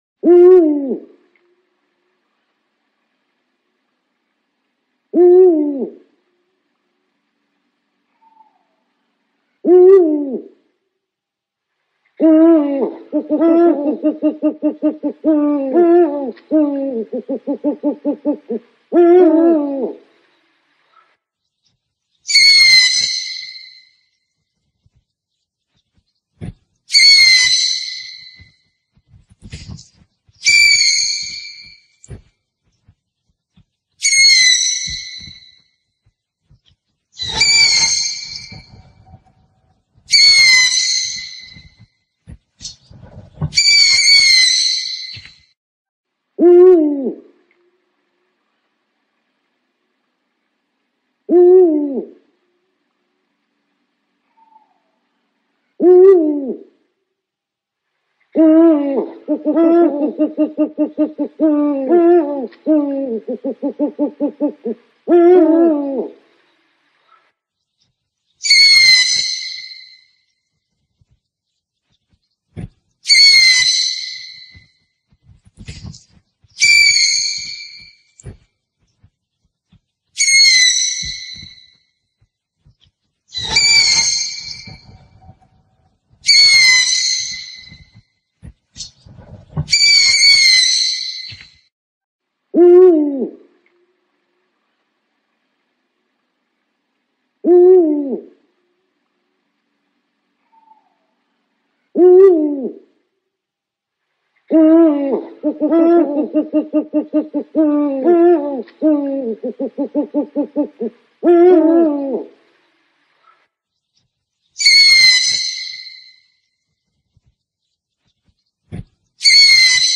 เสียงนกฮูก นกฮูกร้อง นกเค้า นกเค้าแมว เสียงชัด100%
เสียงนกแสกที่เข้าไวมากถึง 100% mp3 โหลดเสียงนกฮูก นกฮูกร้อง นกเค้า และนกเค้าแมวที่มาพร้อมเสียงชัดสุดๆ 100% mp3 4sh คุณสามารถดาวน์โหลดเสียงที่คุณต้องการเพื่อใช้ในการล่อนก (เสียงล่อ).
แท็ก: เสียงนกล่าเหยื่อ เสียงนกเค้าแมว
tieng-chim-lon-cu-meo-keu-chuan-100-www_tiengdong_com.mp3